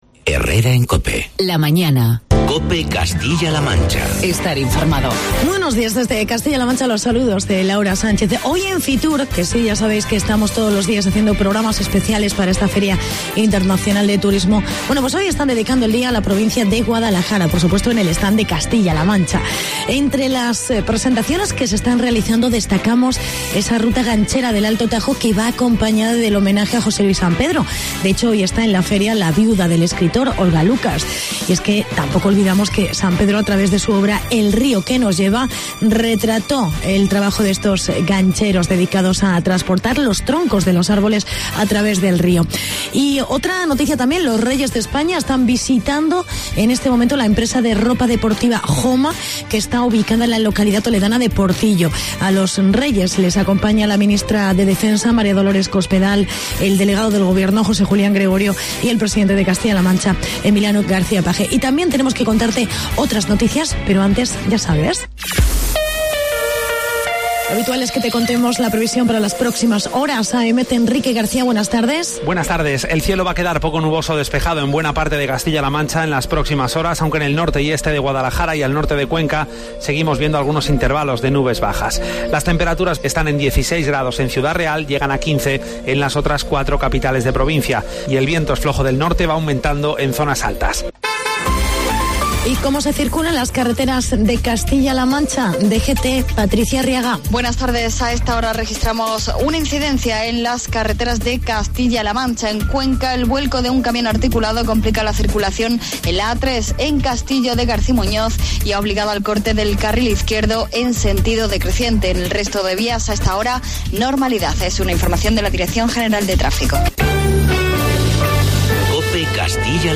Entrevista con la Consejera Patricia Franco